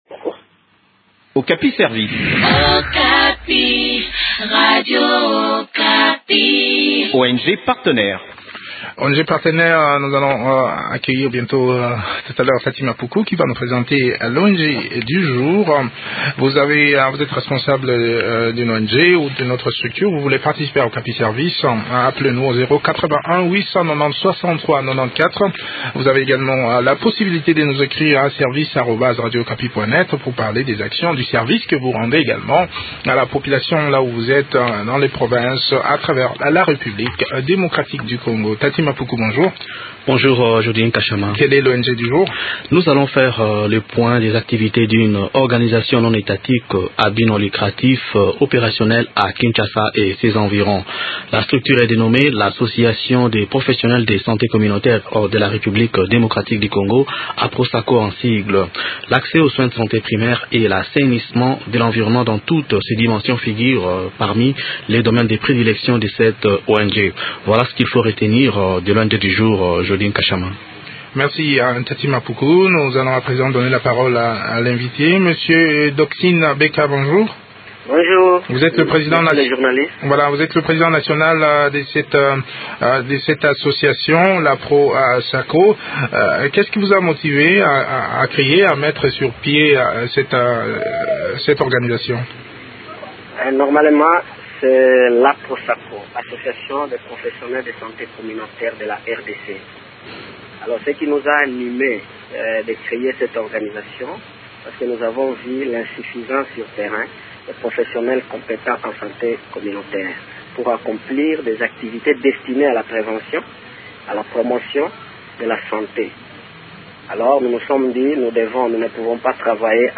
Découvrons les activités de cette ONG dans cet entretien